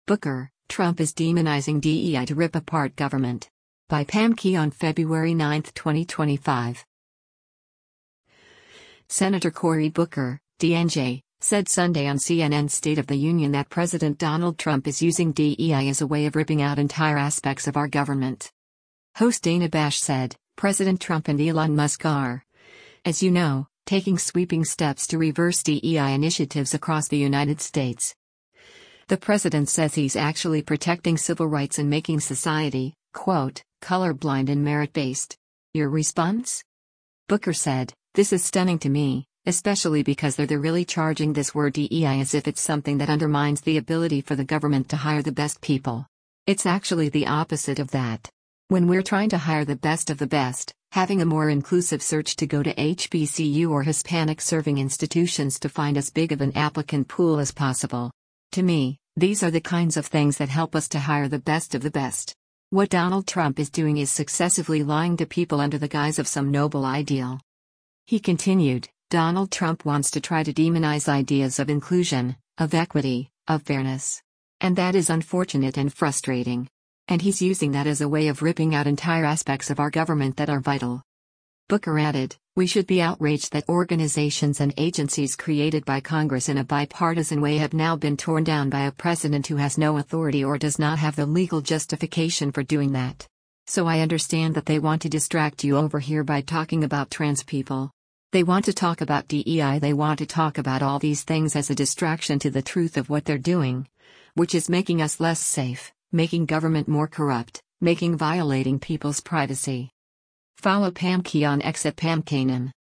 Senator Cory Booker (D-NJ) said Sunday on CNN’s “State of the Union” that President Donald Trump is “using” DEI as a way of “ripping out entire aspects of our government.”